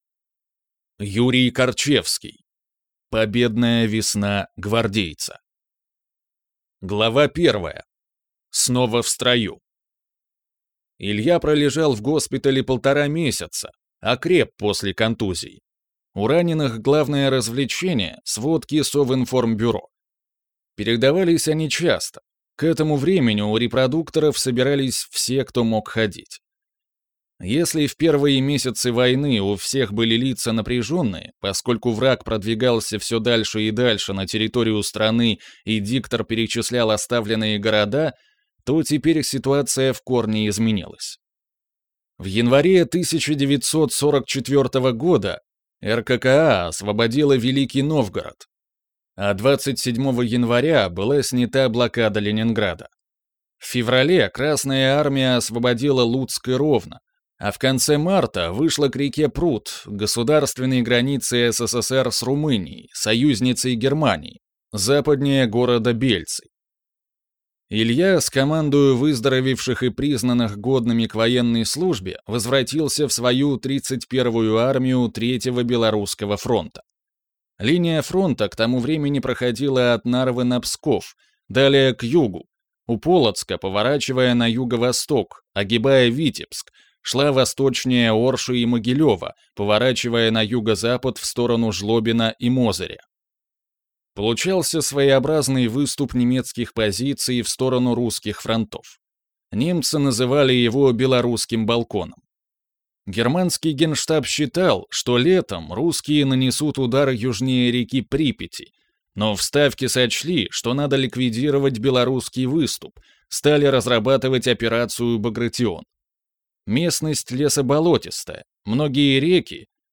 Аудиокнига Победная весна гвардейца | Библиотека аудиокниг